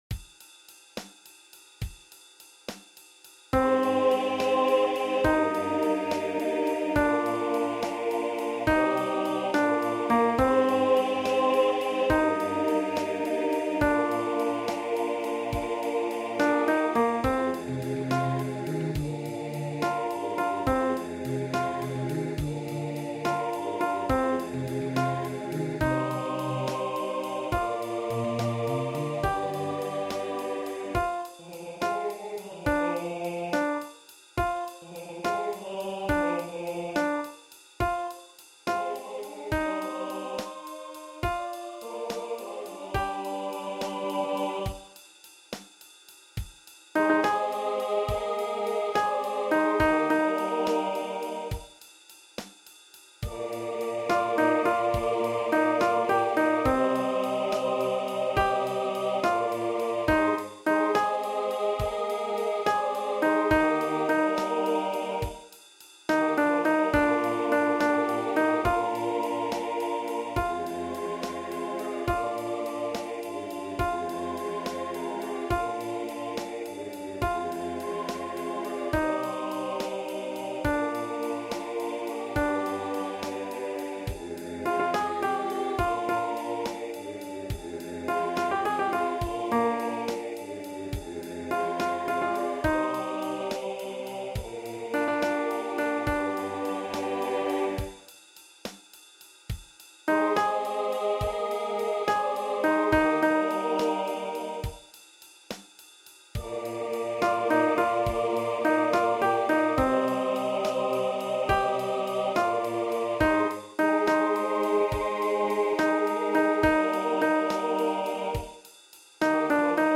Creuser la mémoire de la boue Alto
Creuser_la_memoire_de-la-boue_alt.mp3